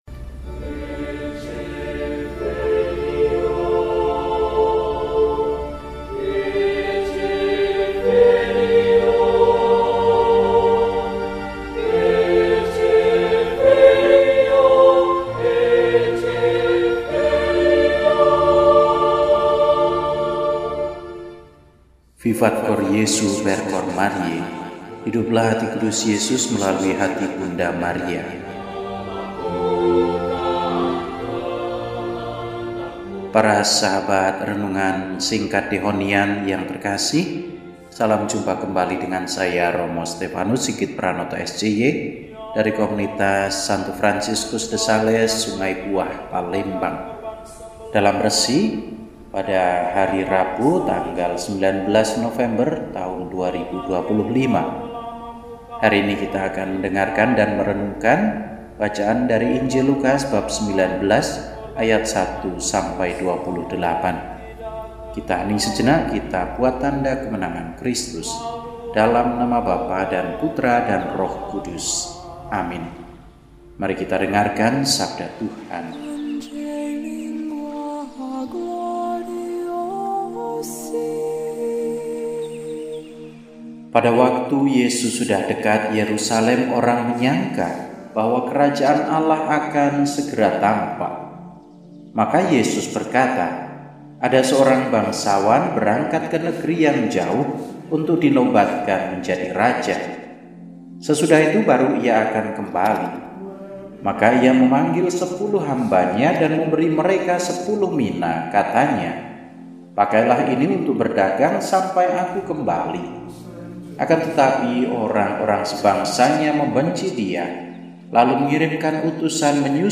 Rabu, 19 November 2025 – Hari Biasa Pekan XXXIII – RESI (Renungan Singkat) DEHONIAN